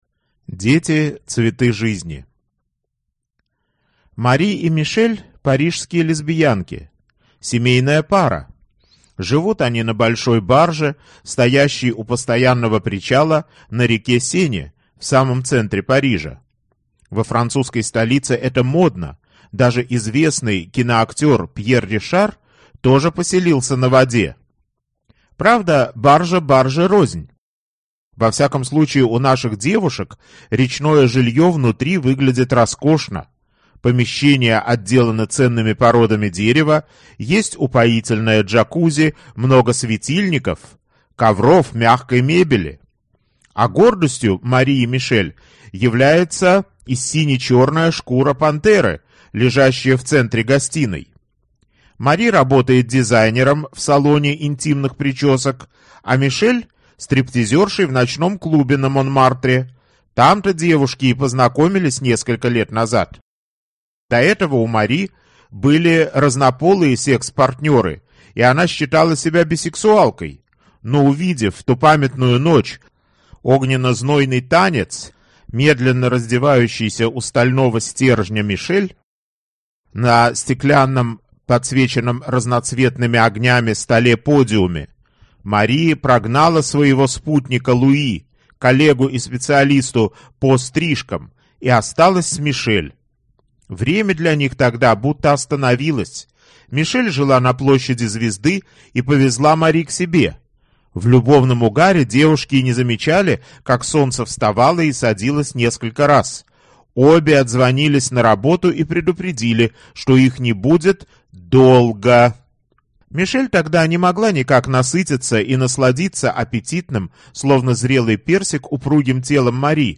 Аудиокнига Жаркая любовь баронессы Лейлы | Библиотека аудиокниг